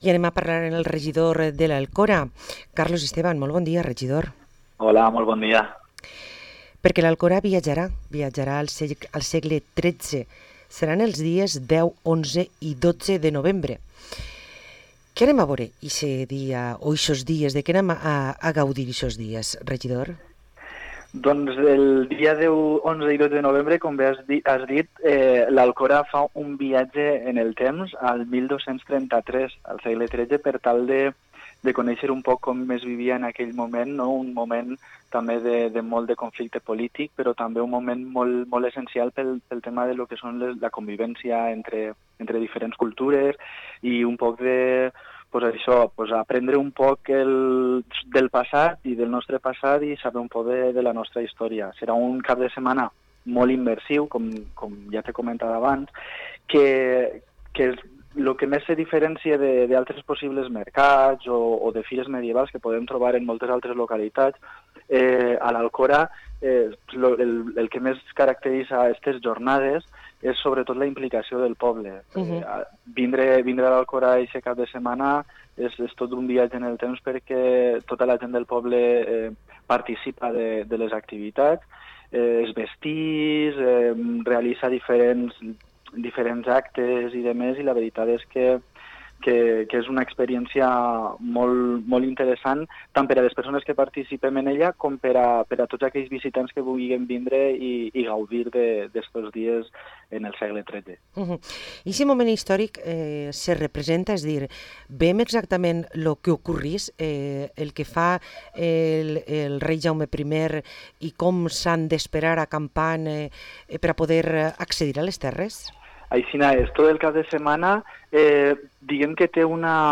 Parlem amb Carlos Esteban, regidor de l´Alcora, ens parla de «Al-qüra Medieval»
23-10-23-CARLOS-ESTEBAN-REGIDOR-ALCORA.mp3